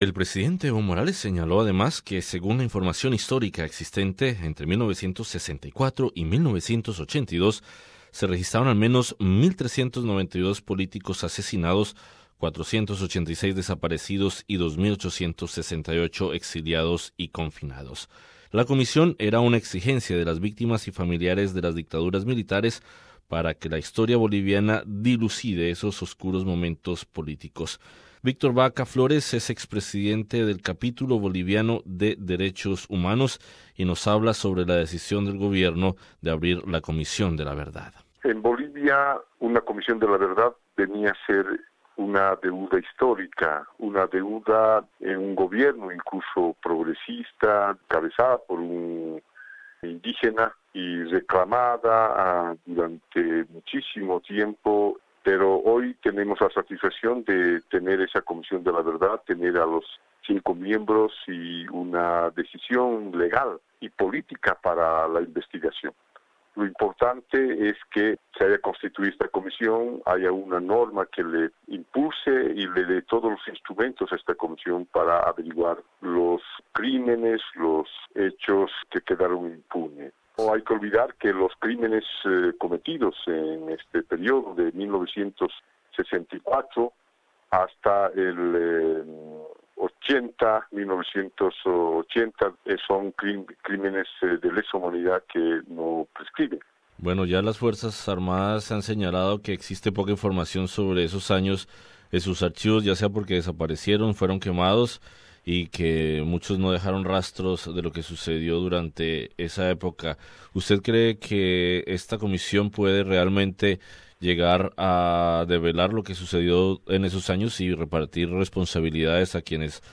Entrevistados